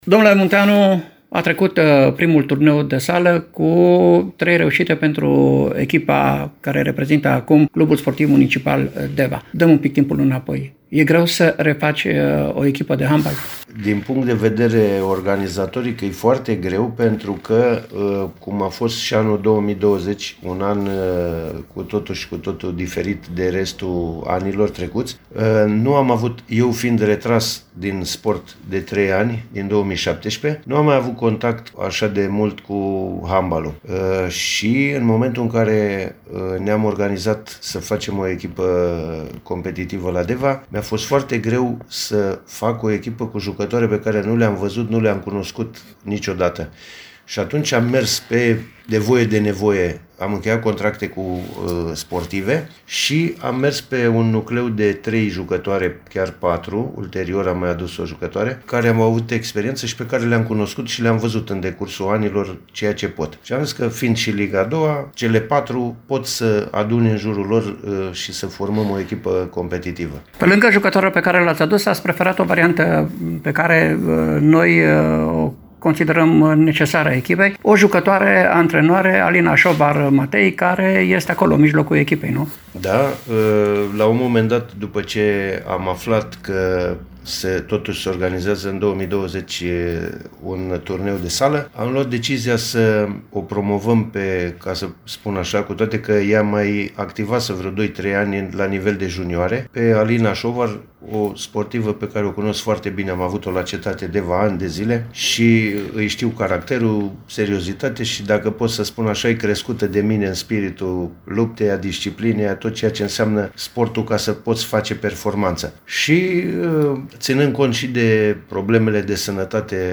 Interviul integral